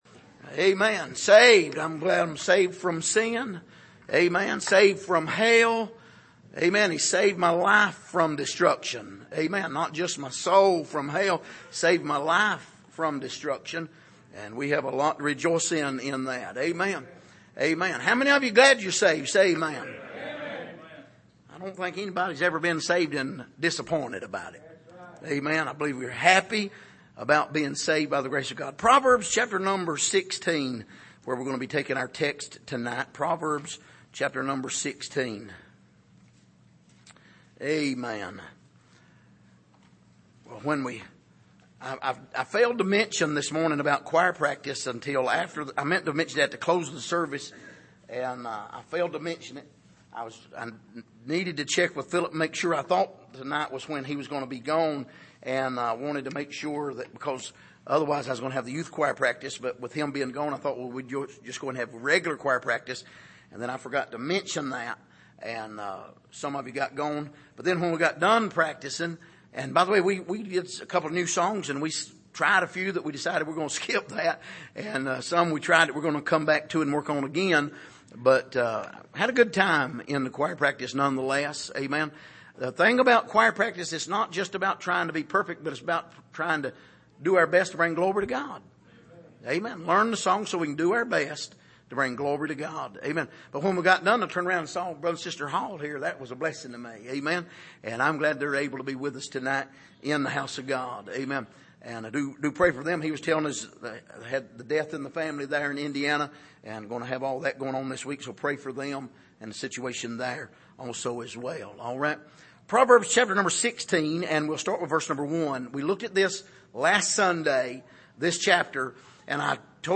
Passage: Proverbs 16:1-15,33 Service: Sunday Evening